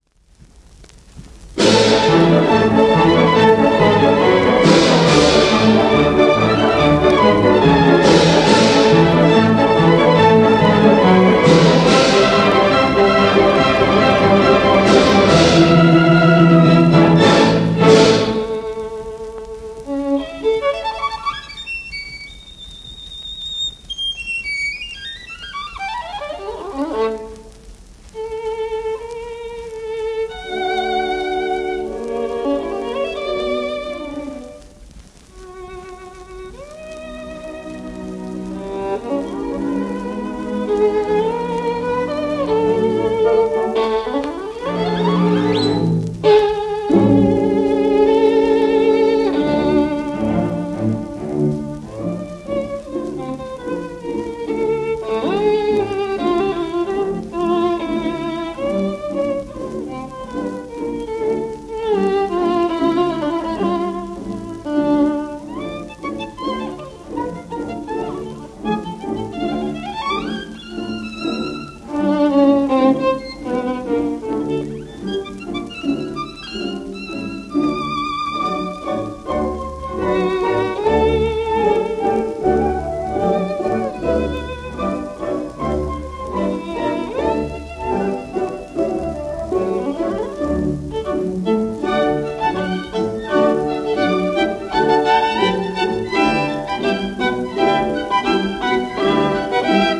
1946年録音